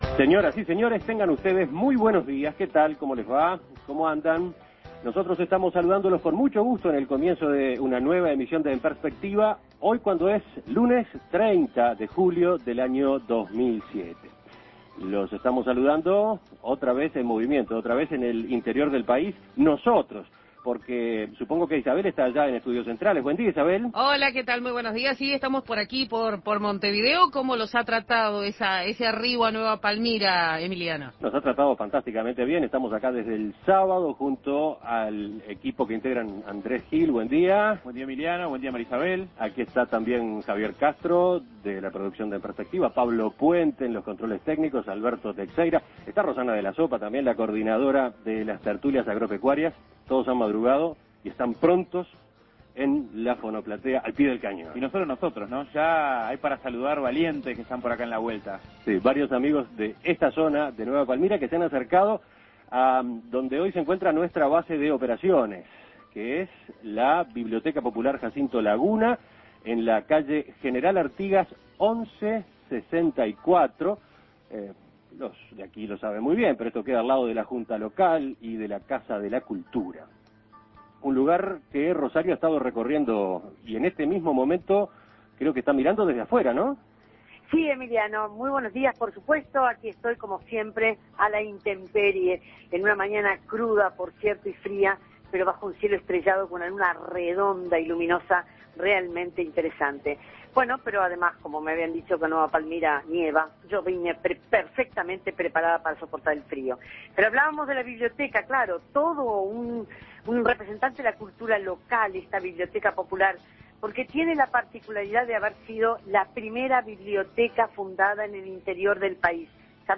El comienzo del programa desde Nueva Palmira, en la Biblioteca Popular Jacinto Laguna